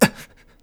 hurt7.wav